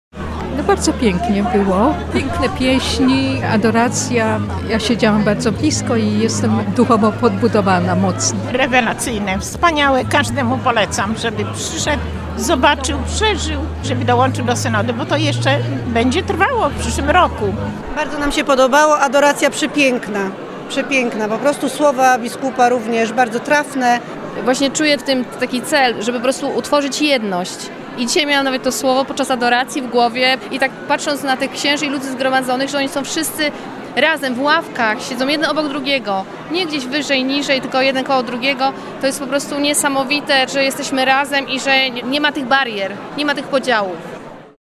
Blisko tysiąc osób uczestniczyło w niedzielę 26 listopada w Diecezjalnym Spotkaniu Zespołów Presynodalnych w Kościele NMP na Piasku we Wrocławiu.